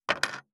583まな板の上,包丁,ナイフ,調理音,
効果音